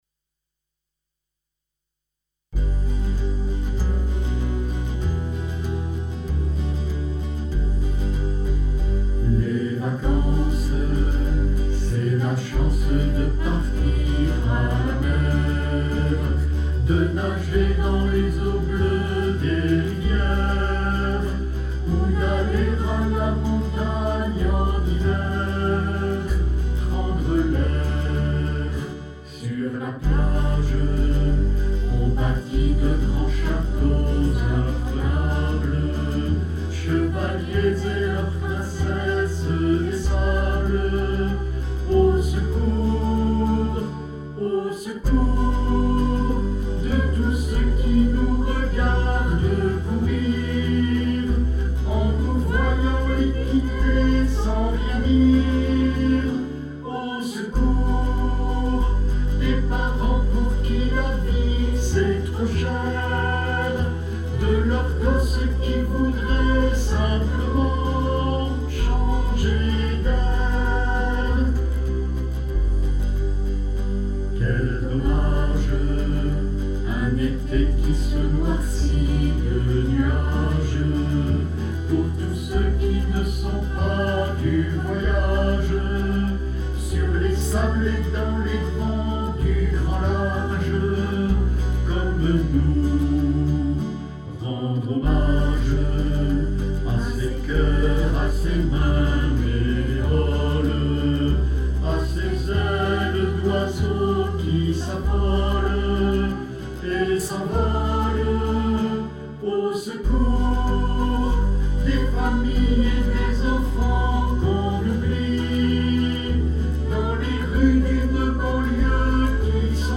Au cours du concert, les choristes de La Viva vous inviteront à chanter "Devoir de vacances" sur une musique facile et joyeuse. Le texte a été écrit pour valoriser les engagements du Secours Populaire en faveur des enfants privés de vacances.
CHANT